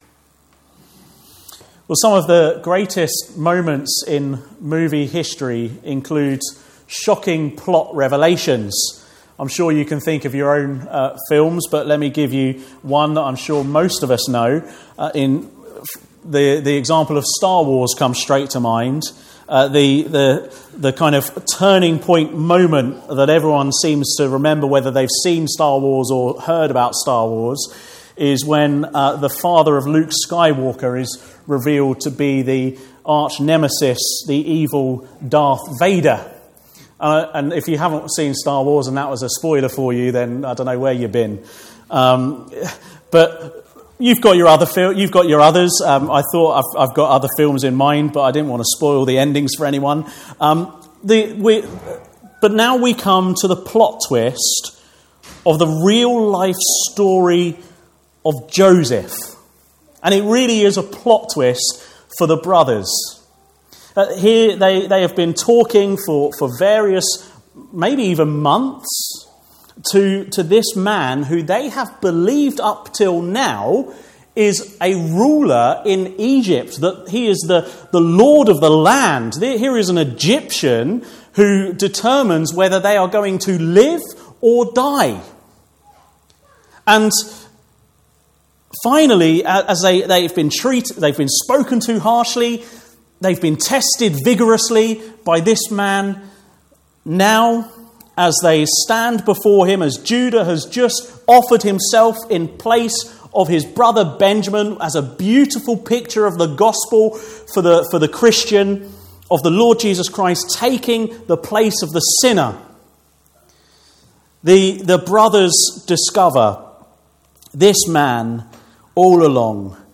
Joseph Passage: Genesis 45:1-28 Service Type: Sunday Afternoon « The Testing Son What are means through which ordinary Christians grow?